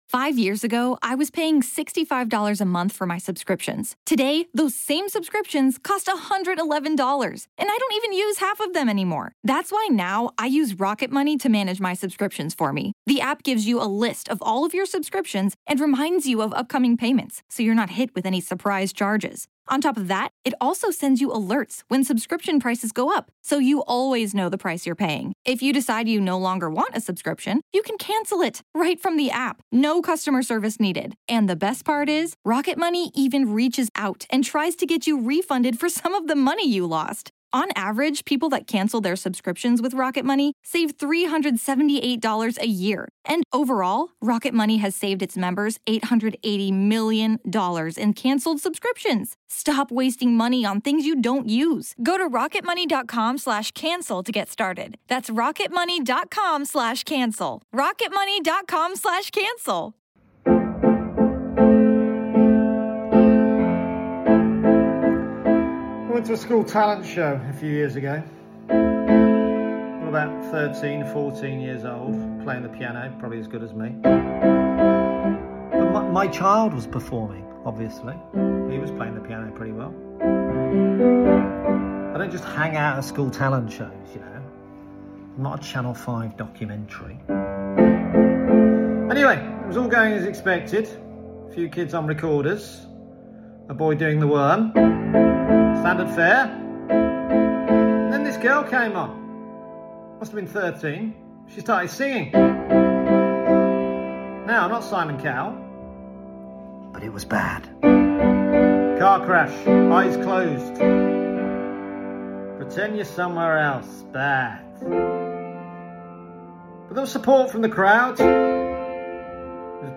Awful singer at a kids talent show.